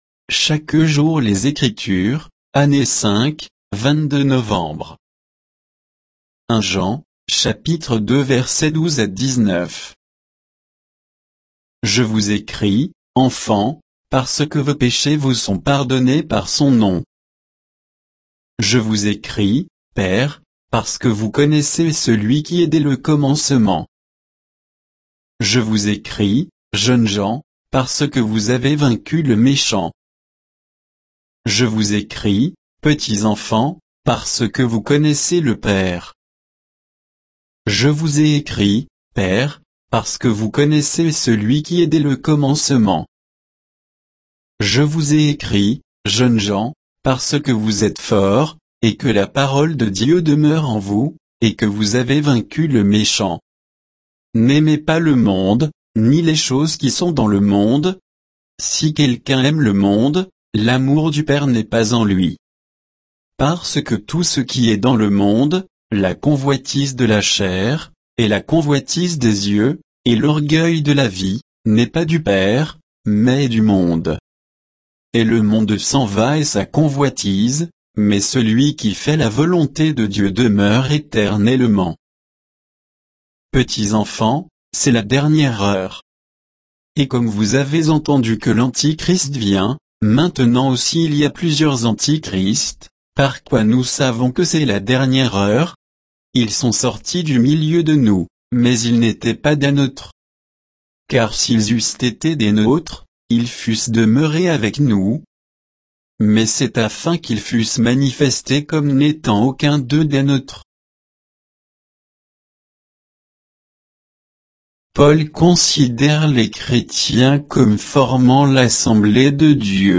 Méditation quoditienne de Chaque jour les Écritures sur 1 Jean 2, 12 à 19